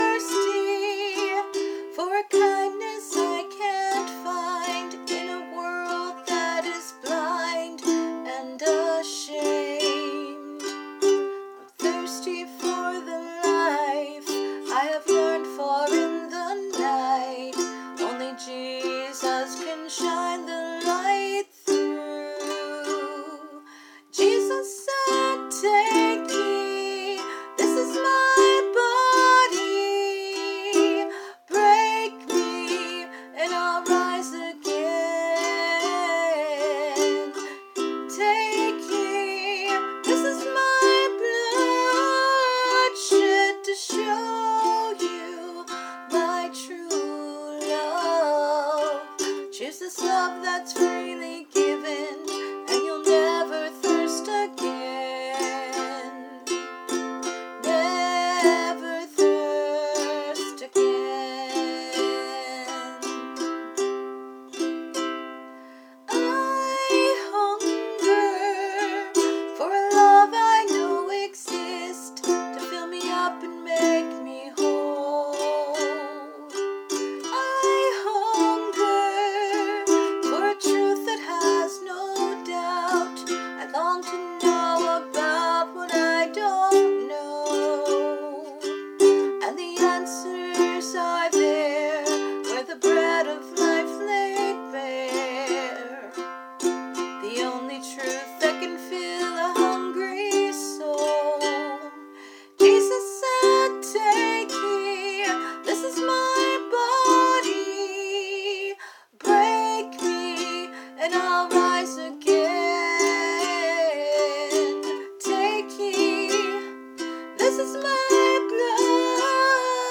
I wrote this as a song for Passover/Easter... recently able to put to music!